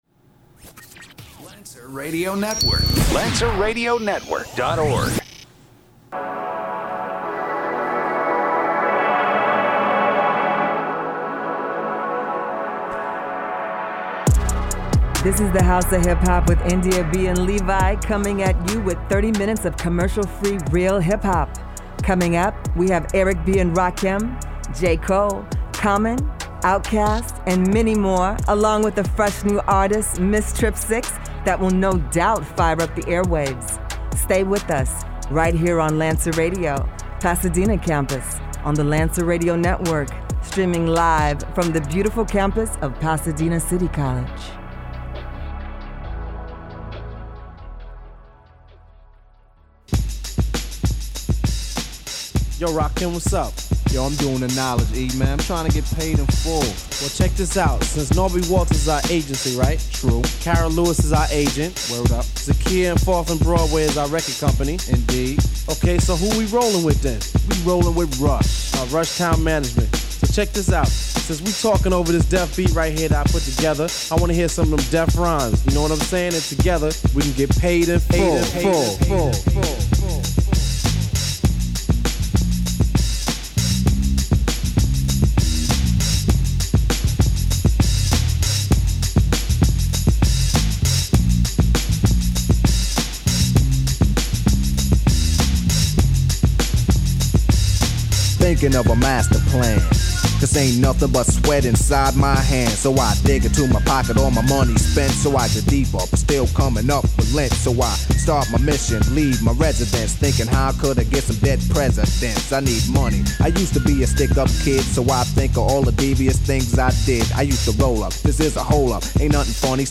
playing the greatest hip hop of yesterday and today.